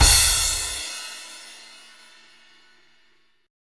taiko-normal-hitfinish.ogg